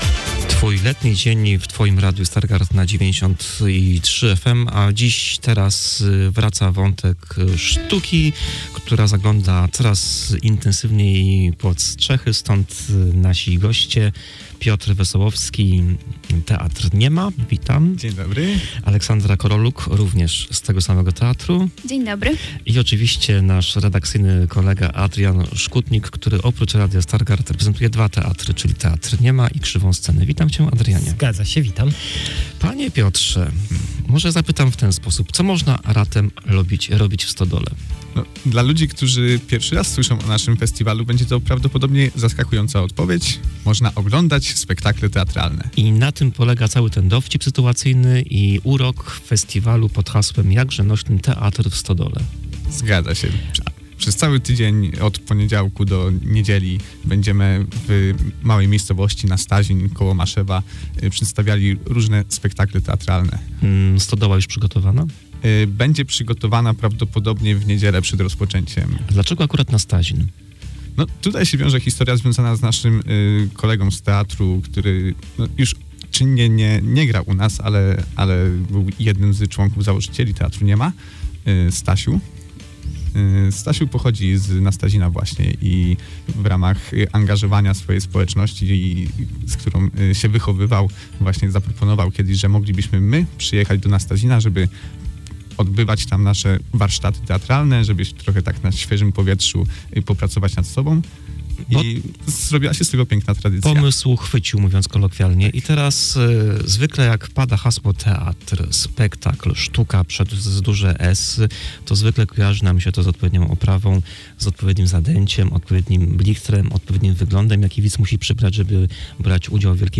O magii festiwalu i tym co czeka nas podczas najbliższej 14 edycji, opowiadali na naszej antenie aktorzy Teatru „Nie Ma”